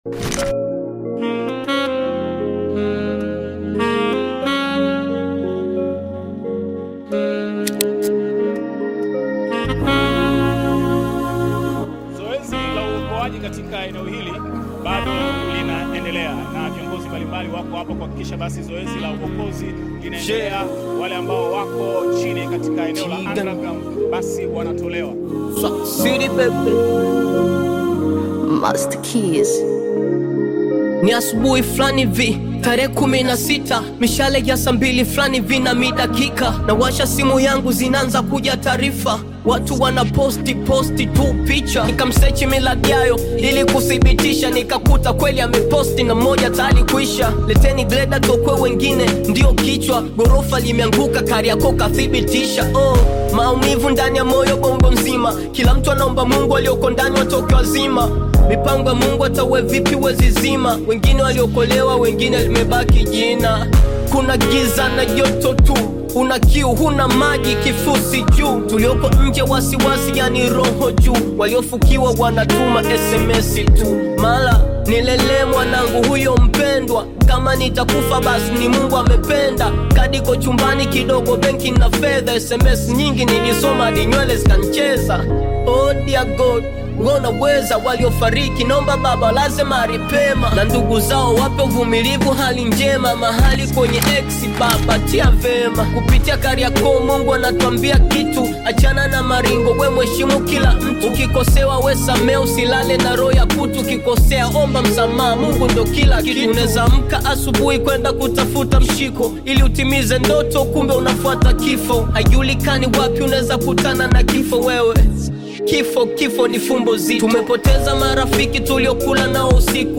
Bongo Flava song